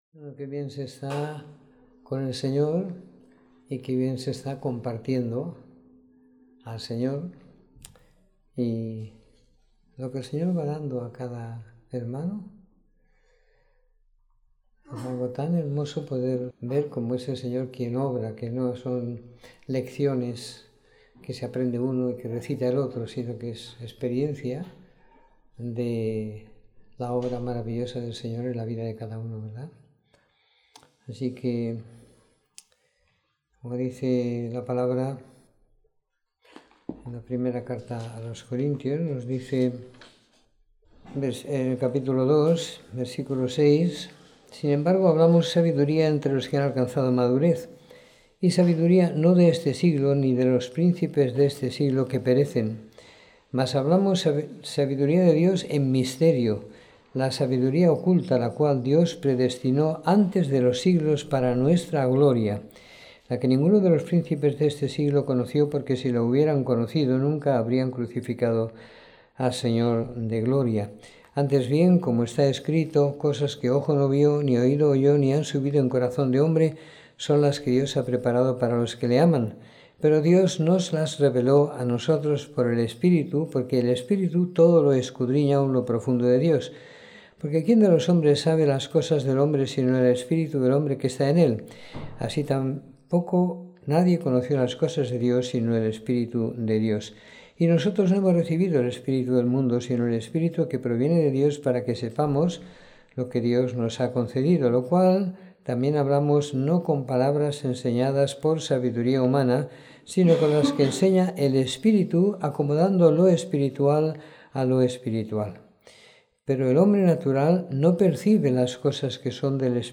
Domingo por la Tarde . 27 de Diciembre de 2015